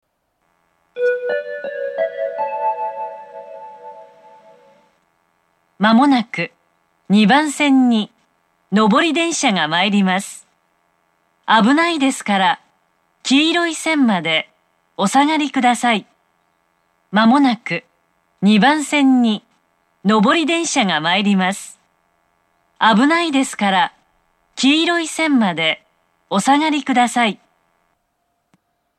２０１２年１２月中旬に放送装置が更新され、音質が向上し、メロディーの音程が下がりました。
２番線接近放送
２番線発車メロディー 曲は「Water Crown」です。音程は低いです。